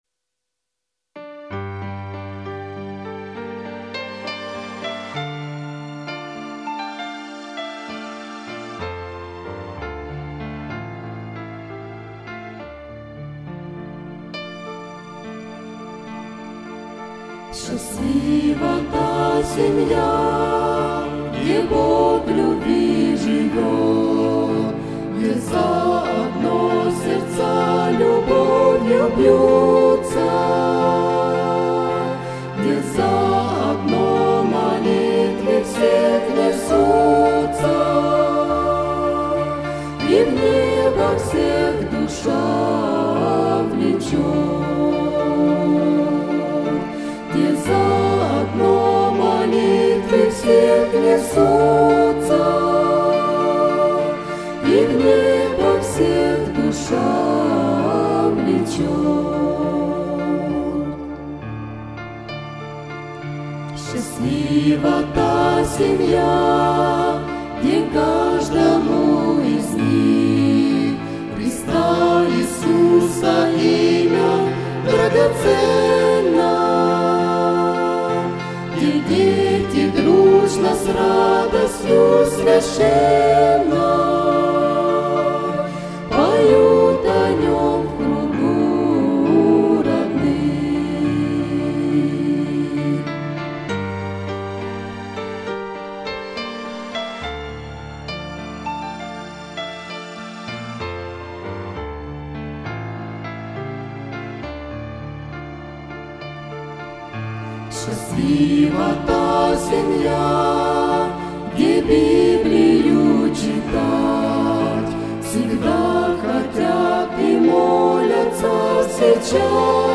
on 2015-12-27 - Молитвенное пение